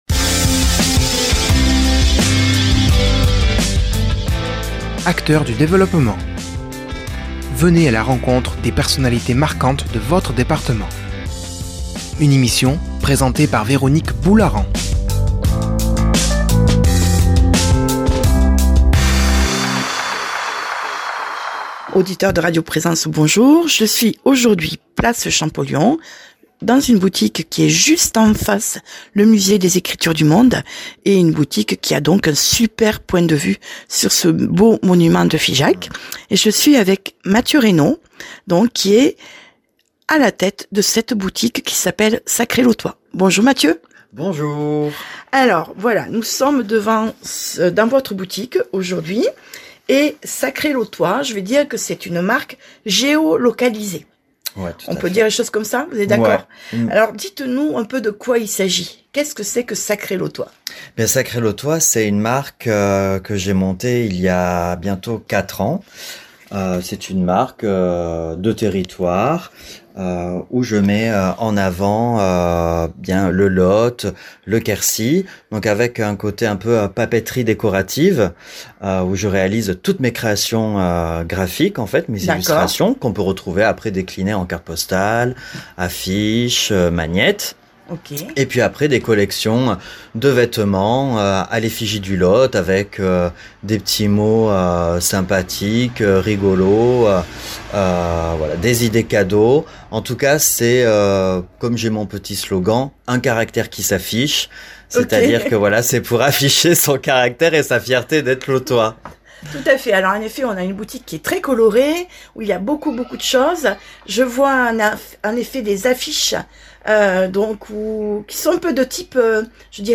Elle se trouve Place Champollion à Figeac